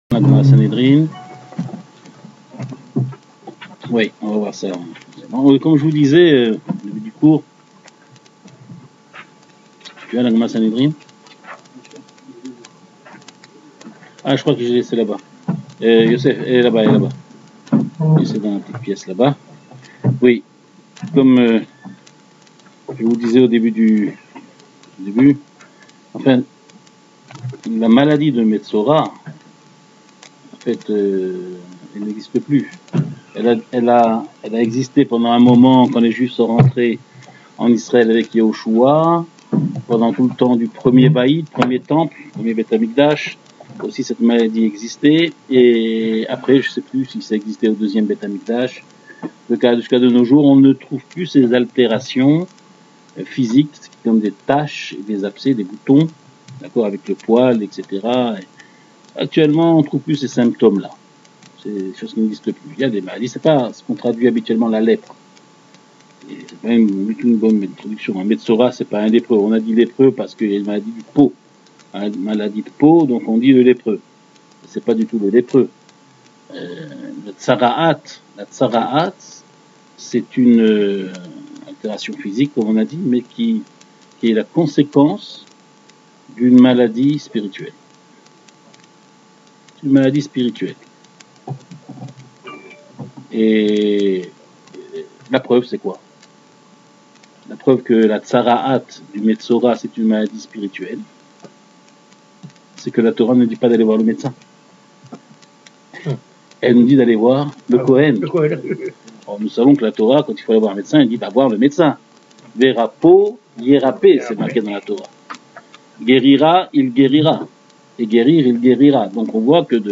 Le cours est donné tous les jeudis soir.